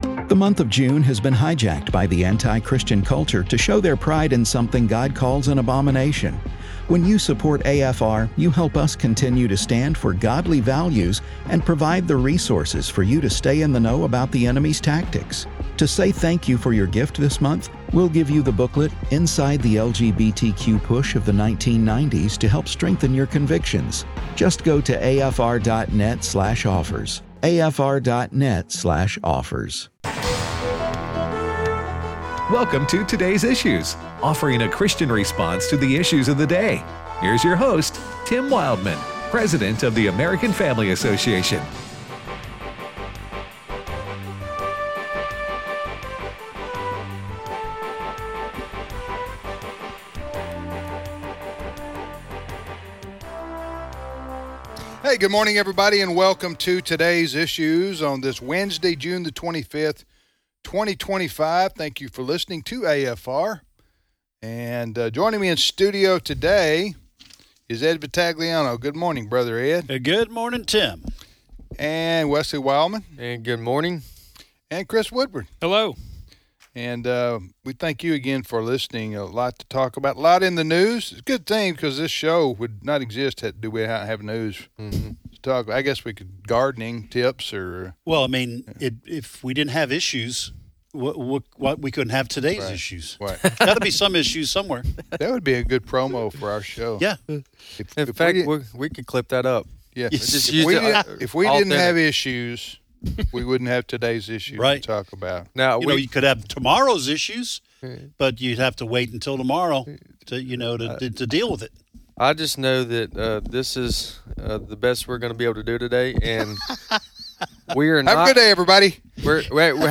Also, Jenna Ellis joins the program to discuss the latest Supreme Court ruling.